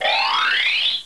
MG_pos_buzzer.ogg